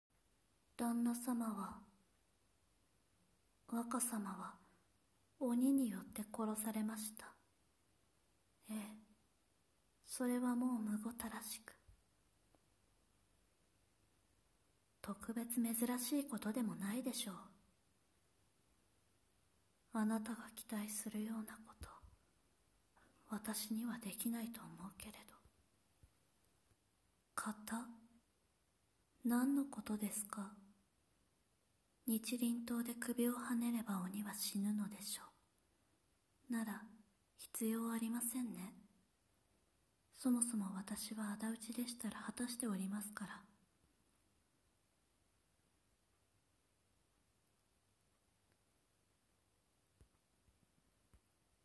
サンプルボイス 応募用